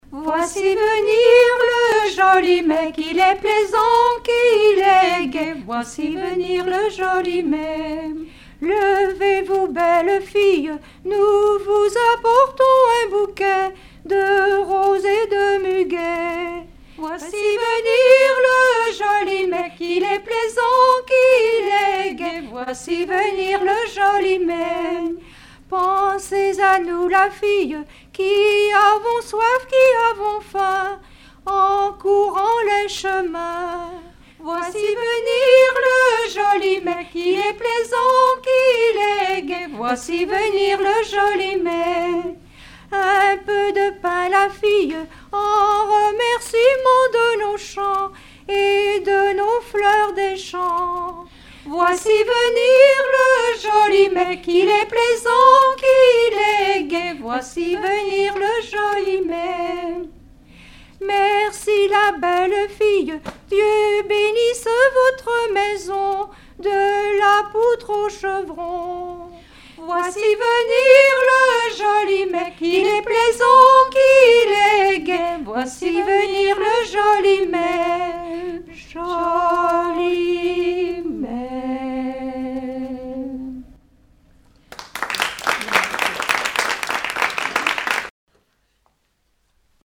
Genre strophique
Collectif de chanteurs du canton - veillée (2ème prise de son)
Pièce musicale inédite